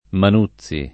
[ man 2ZZ i ]